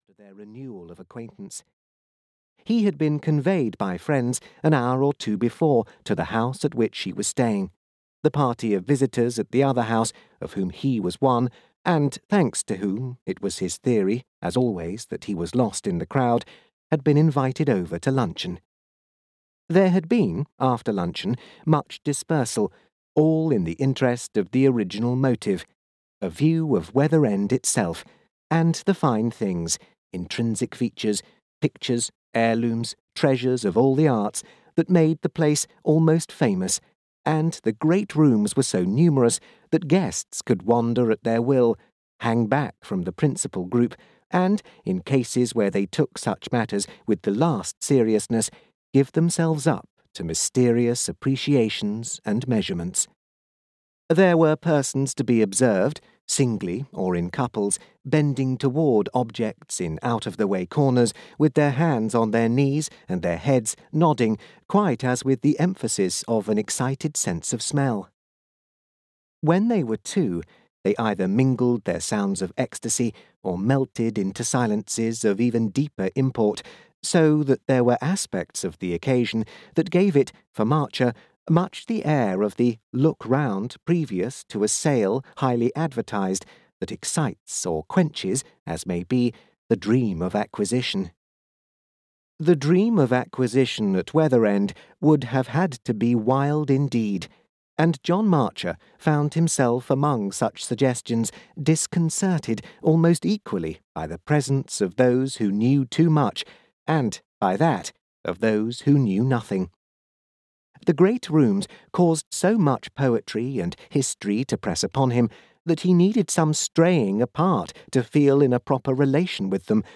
The Beast in the Jungle (EN) audiokniha
Ukázka z knihy